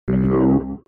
دانلود صدای ربات 6 از ساعد نیوز با لینک مستقیم و کیفیت بالا
جلوه های صوتی